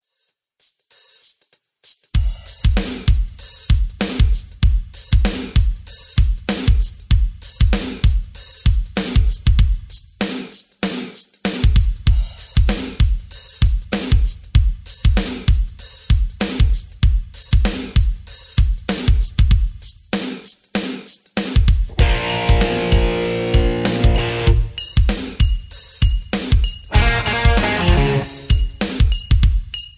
karaoke, mp3 backing tracks
rock, hip hop, rap, beat tracks